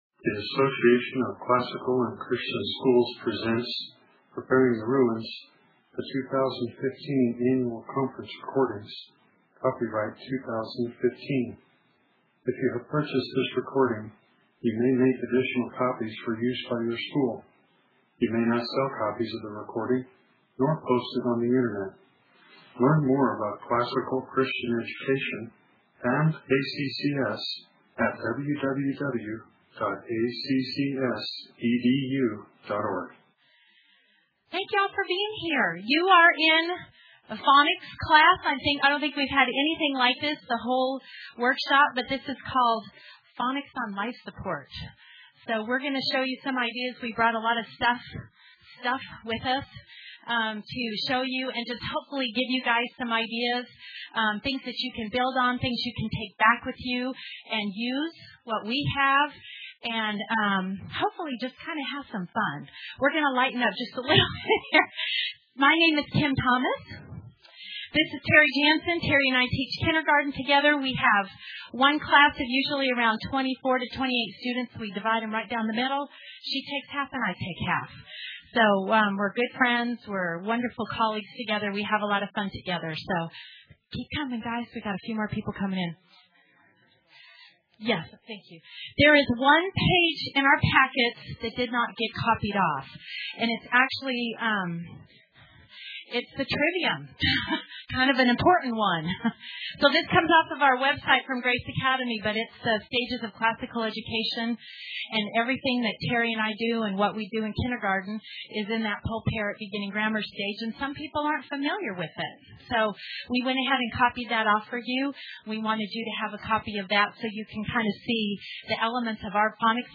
2015 Workshop Talk | 0:52:09 | K-6
Jan 11, 2019 | Conference Talks, K-6, Library, Media_Audio, Workshop Talk | 0 comments
Additional Materials The Association of Classical & Christian Schools presents Repairing the Ruins, the ACCS annual conference, copyright ACCS.